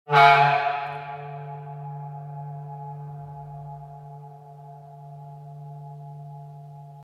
Klingelton 1 (Klarinettenvibrato)